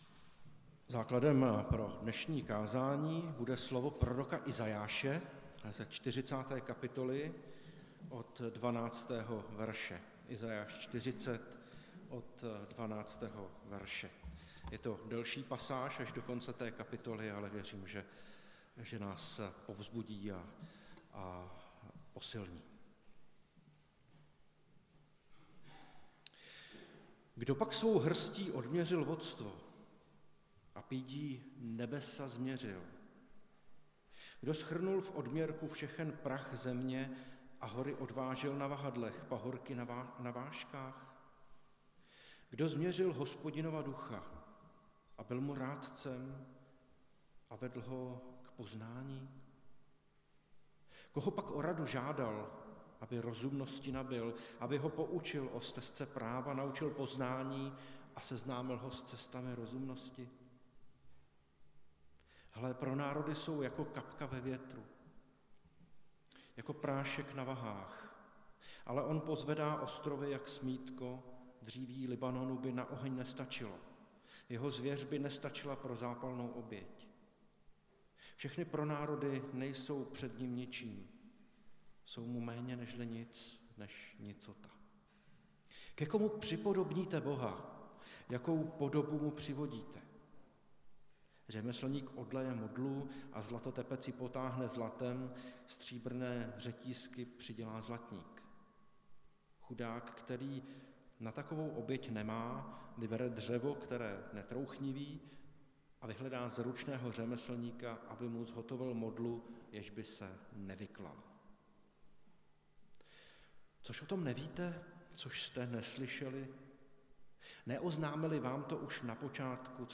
Nedělní kázání – 1.1.2023 Novoroční kázání